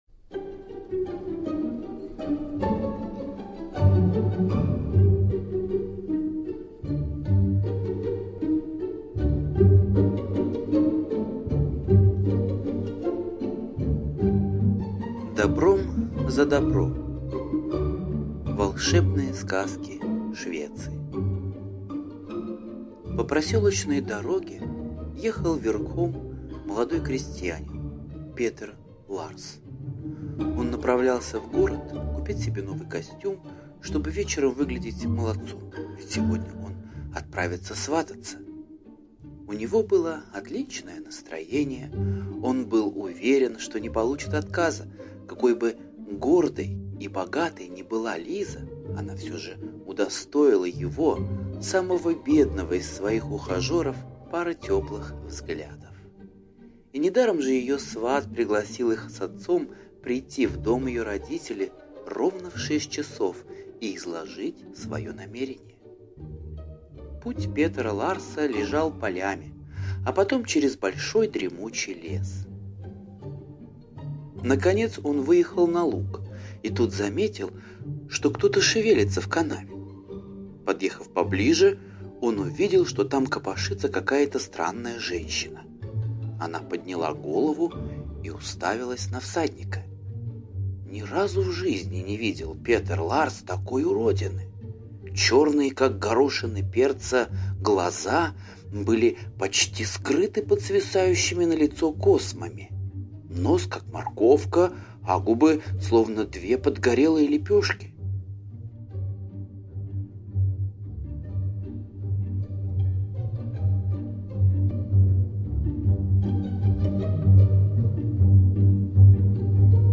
Добром за добро - скандинавская аудиосказка - слушать онлайн